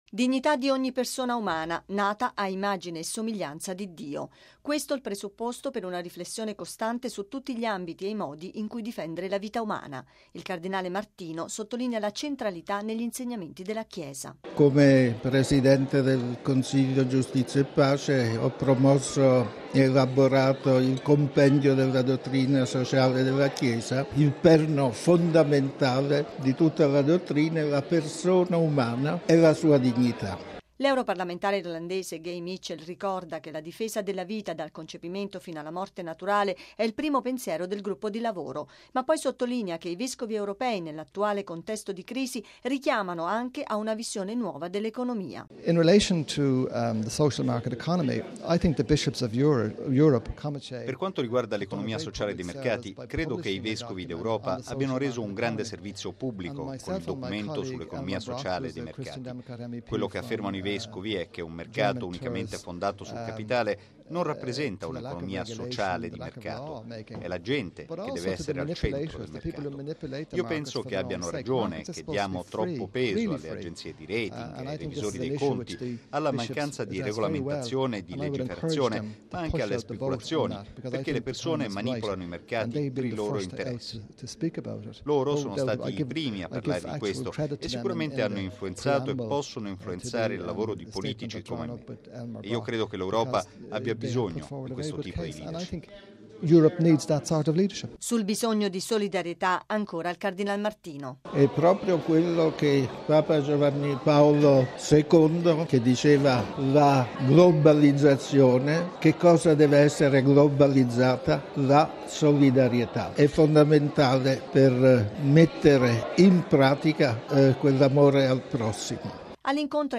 Con l’incontro di ieri e oggi, viene annunciata la nascita del working group in Italia.